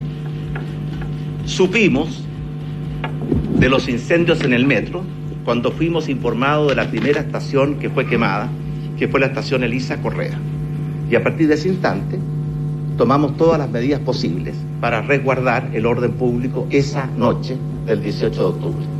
Ante la polémica, Piñera volvió a conversar con la prensa en la presentación de la Agenda Antiabusos del Gobierno. Allí se le pidió que profundizara sus dichos y su respuesta fue que la primera información que tuvieron fue tras el primer ataque incendiario.